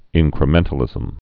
(ĭnkrə-mĕntl-ĭzəm)